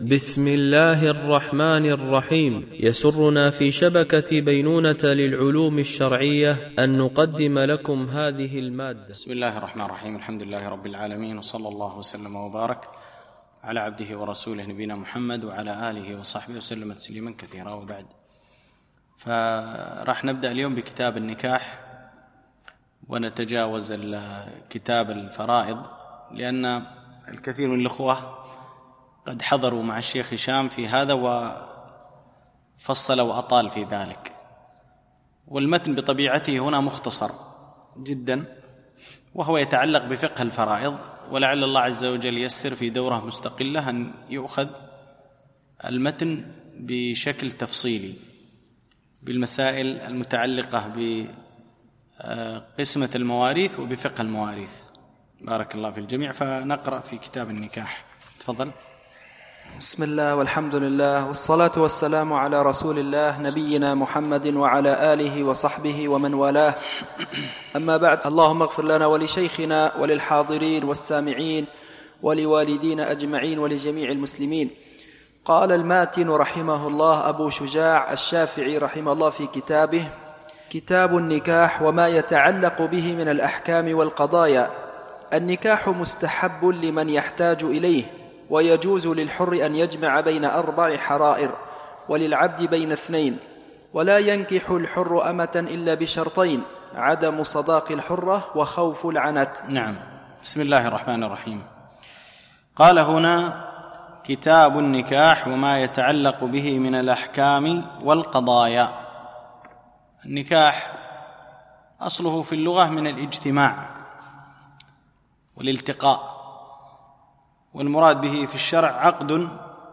شرح متن أبي شجاع في الفقه الشافعي ـ الدرس 32